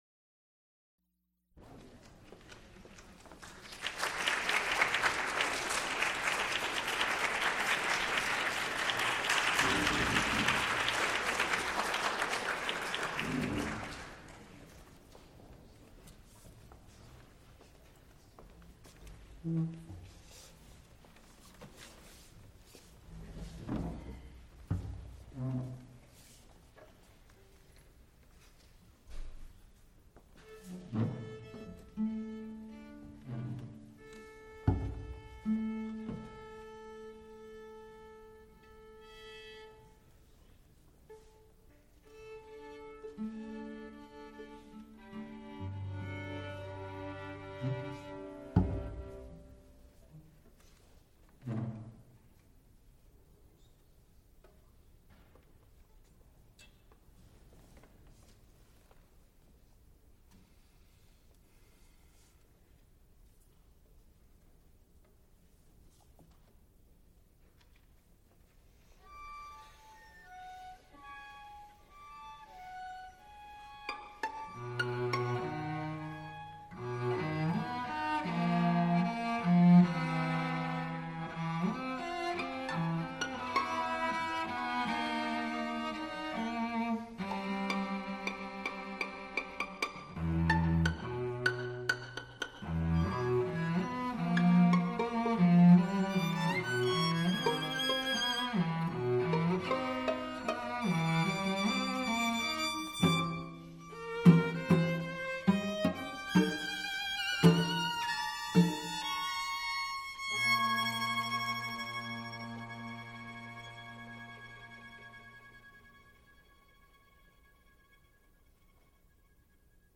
Piano music
String quartets
Music--First performances